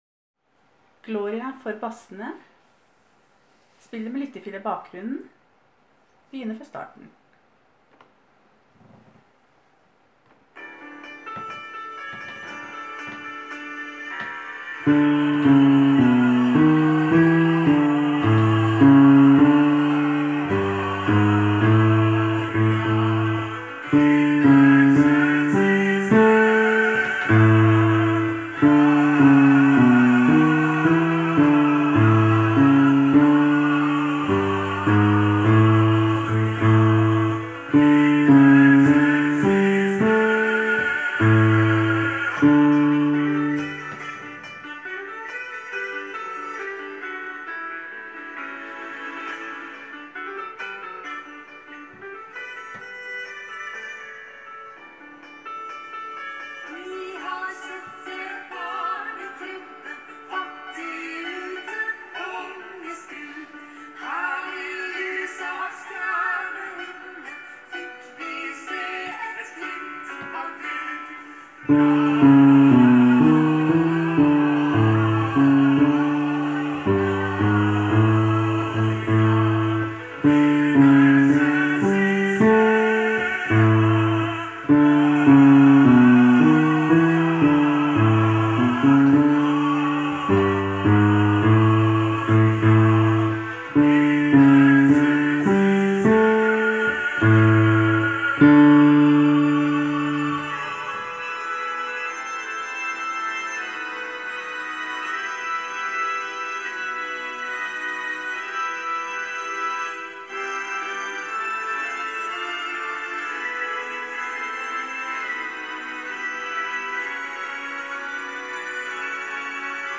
Jul 2017 Bass (begge konserter)
Gloria med lyttefil i bakgrunnen:
Gloria-Basser-Lyttefila-i-bakgrunnen.m4a